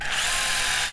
auto_first_barrel_spin1.wav